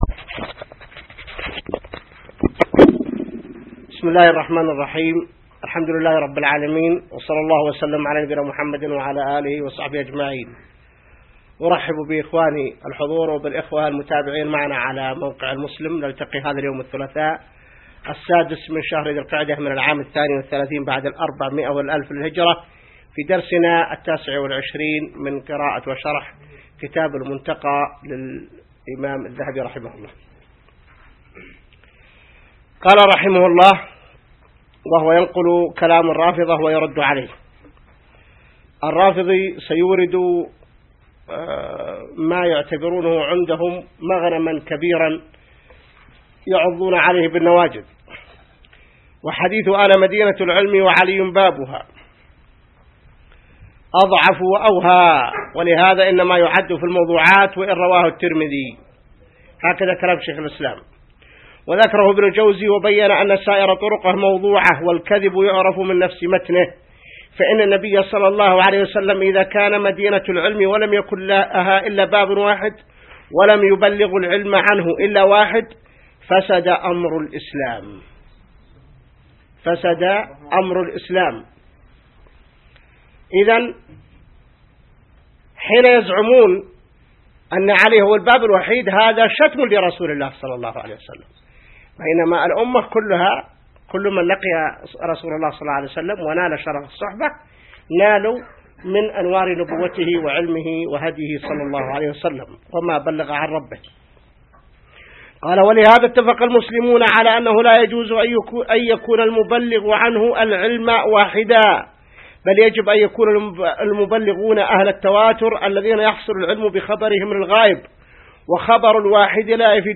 الدرس 29 من شرح كتاب المنتقى | موقع المسلم